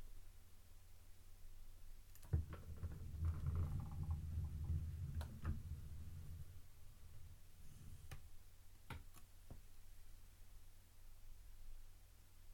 Slow/Open drawer 2
Description - Wooden drawer, opens, slowly, drags, rails, grabs/ holds.